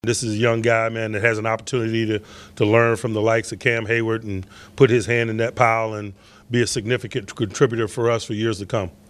Tomlin says Heyward will be a key mentor for Harmon.